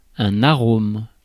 Ääntäminen
France: IPA: [a.ʁom]